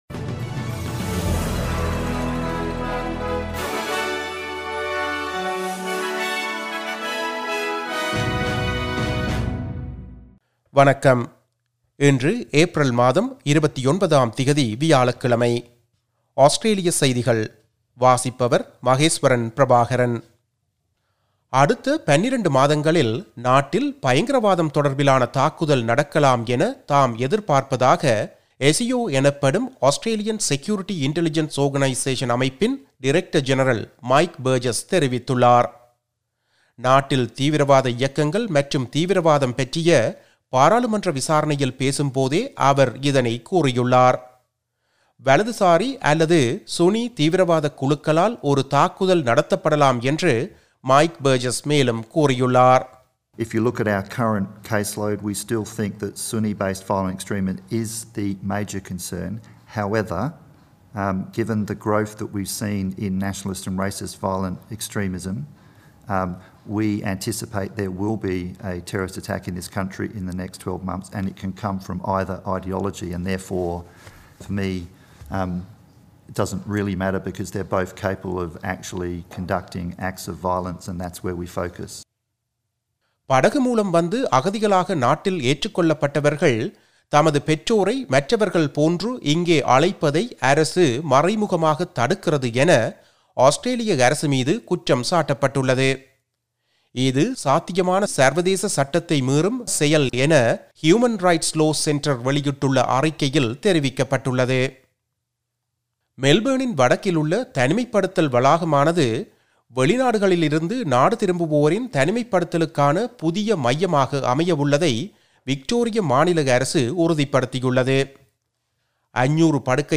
Australian news bulletin for Thursday 29 April 2021.